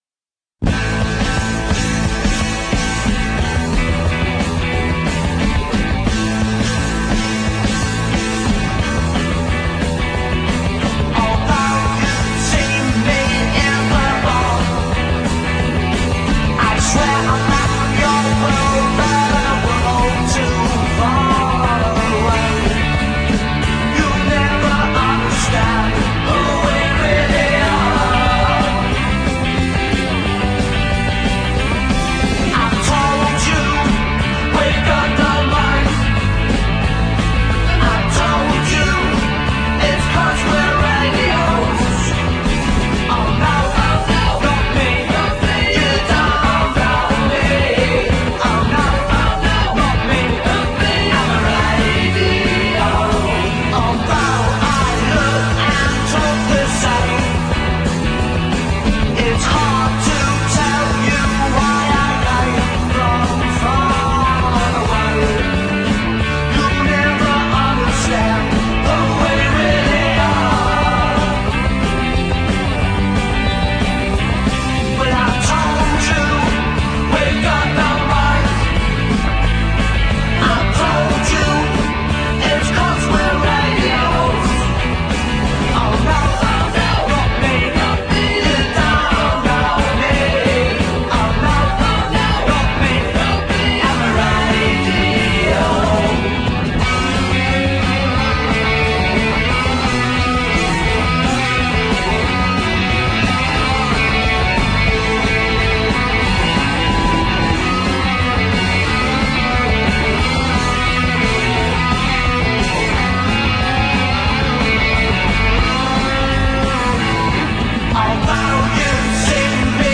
パンク、パワーポップ、スカなどの幅広いジャンル で飽きのこない内容。